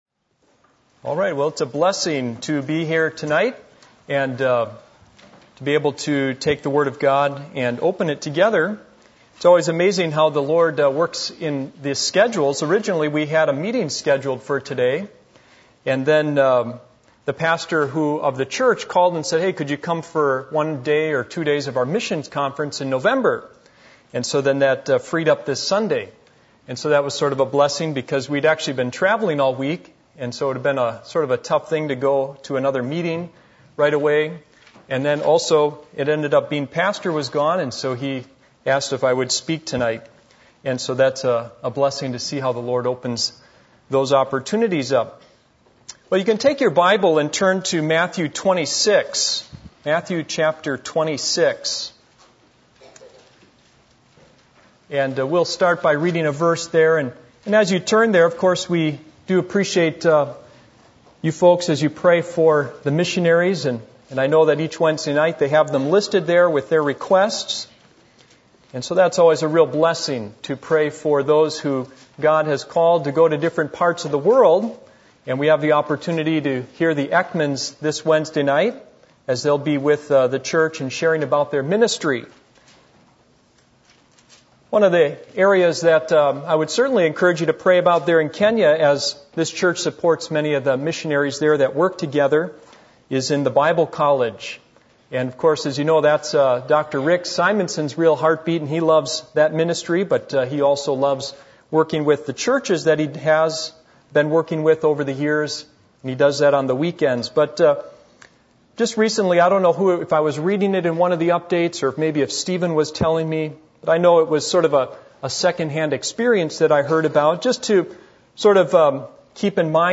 Passage: Matthew 26:30-35 Service Type: Sunday Evening %todo_render% « The Right Set Of Plans For Life Here Am I